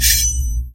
Music Box with Mech. Sound and Slow Down (Dry)
描述：A little melody I made with the DSK Music Box (free plugin pretty nice). There is also the charging sound of the music box, and the mechanical noise of the rotation.
标签： Charged Charging Melody Mechanical Music Quiet ChromaticPercussion MusicBox Noise Mechanics Dry
声道立体声